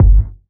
• Low Kick Drum Sample G Key 545.wav
Royality free bass drum single hit tuned to the G note. Loudest frequency: 82Hz
low-kick-drum-sample-g-key-545-m2s.wav